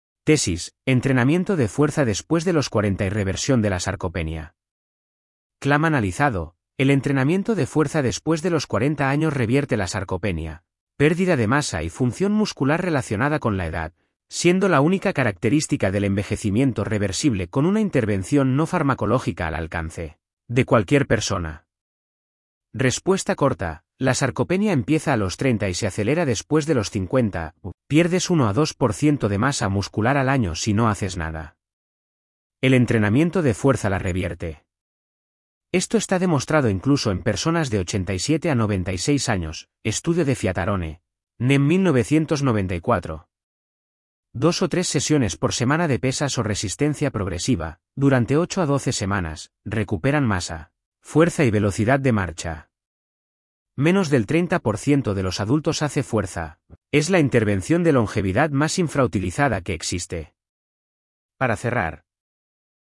Voz: Microsoft Alvaro (es-ES, neural).